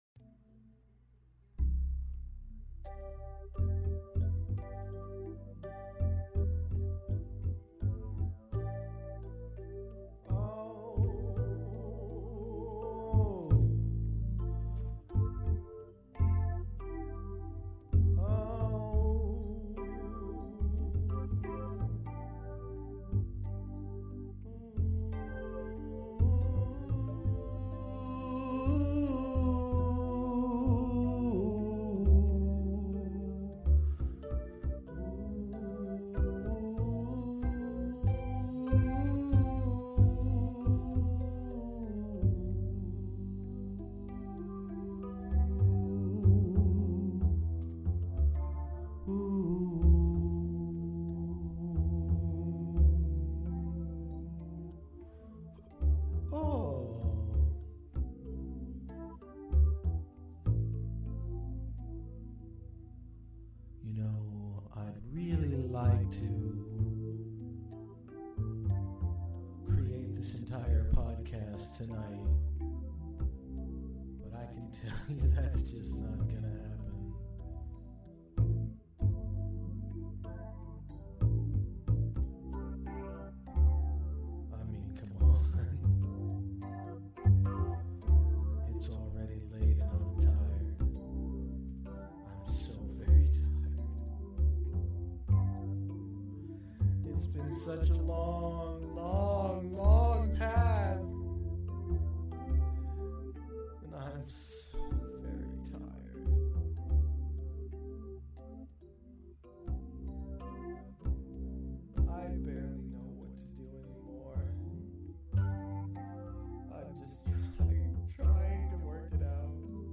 Another vault episode, this one dates back to a time of deep depression. Consequently, I take you all for a nice walk in the park.